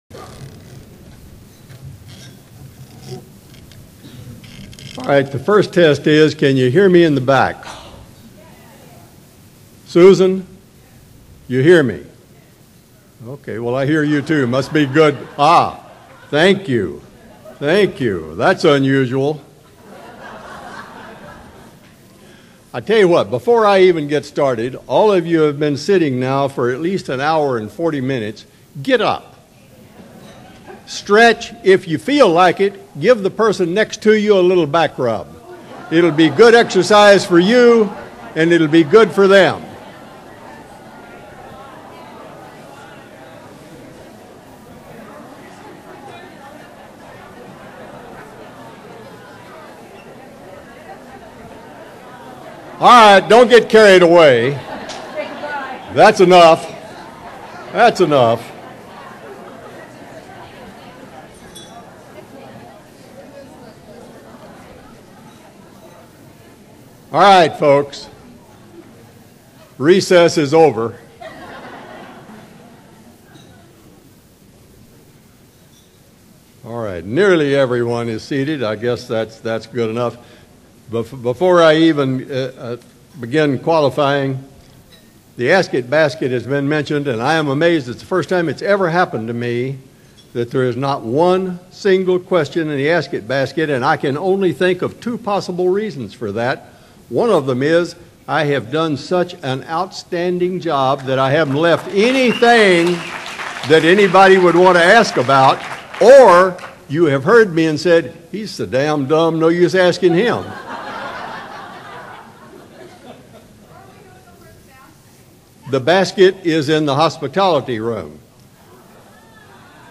Speaker Podcasts & Audio Files
Region 1 Convention, Seattle 2001